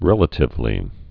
(rĕlə-tĭv-lē)